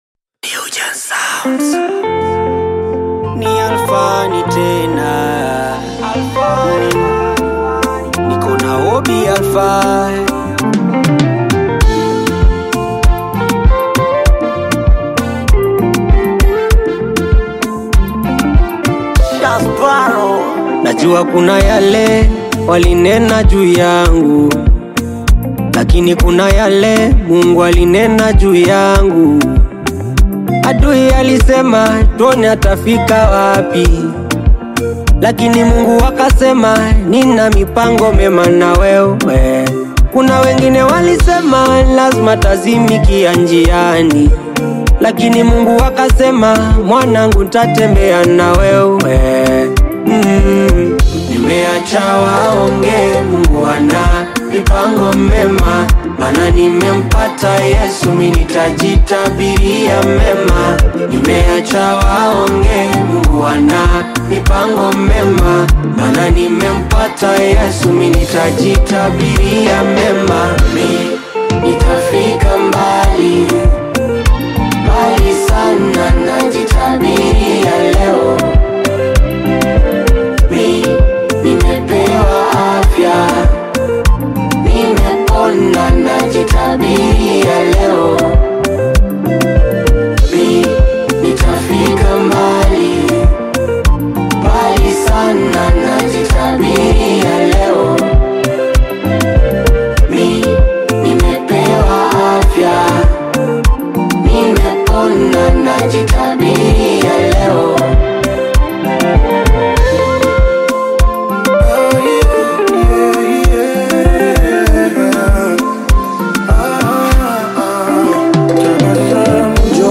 heartfelt melodies
signature gospel depth and uplifting presence.
faith-driven anthem